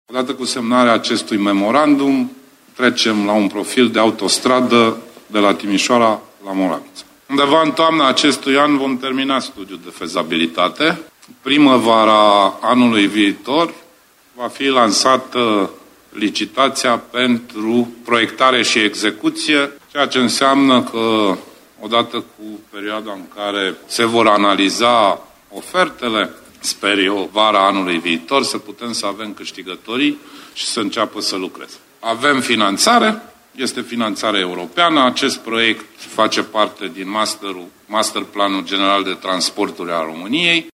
Potrivit lui Sorin Grindeanu, documentul permite ca noul drum de mare viteză, gândit inițial ca un drum expres, să fie construit în regim de autostradă: